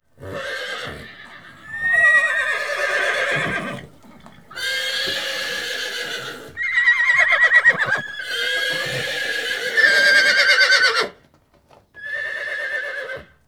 horses.wav